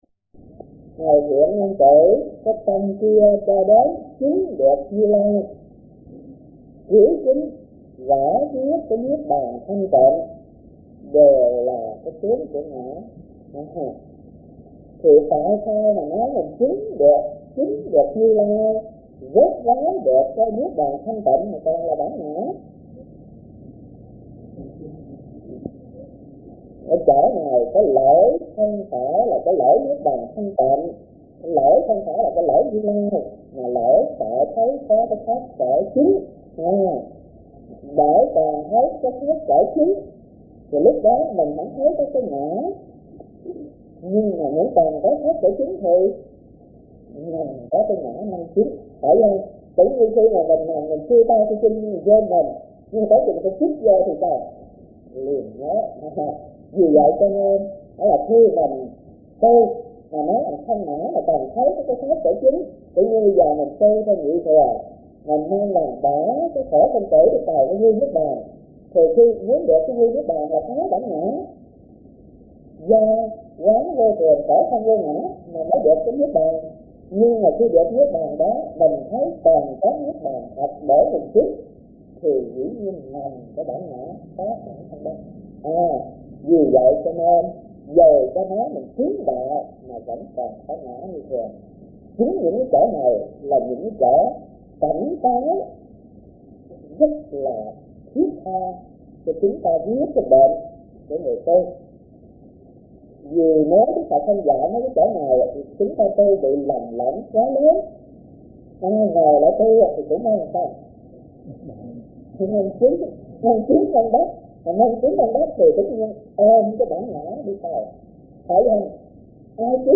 Kinh Giảng Kinh Viên Giác - Thích Thanh Từ